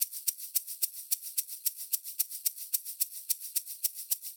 03 Shaker.wav